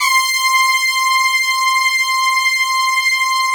BRASS1 MAT.3.wav